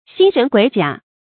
辛壬癸甲 xīn rén guǐ jiǎ
辛壬癸甲发音
成语注音ㄒㄧㄣ ㄖㄣˊ ㄍㄨㄟˇ ㄐㄧㄚˇ